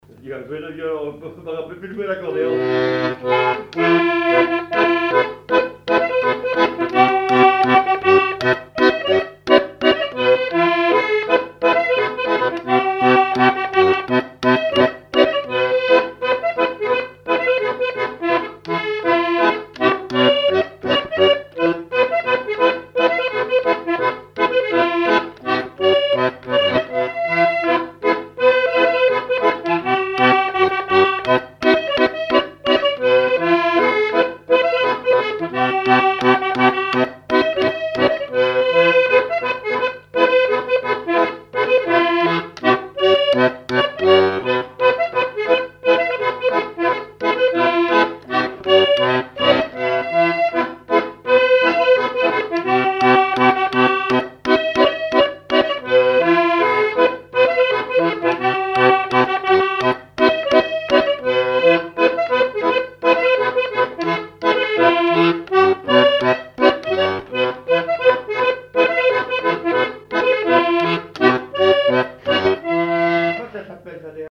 Chants brefs - A danser
scottich trois pas
répertoire d'air pour la danse au violon et à l'accordéon
Pièce musicale inédite